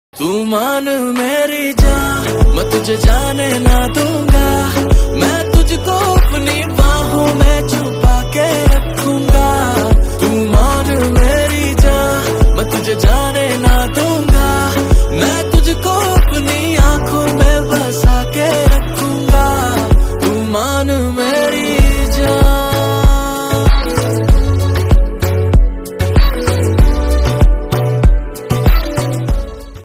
lofi Mix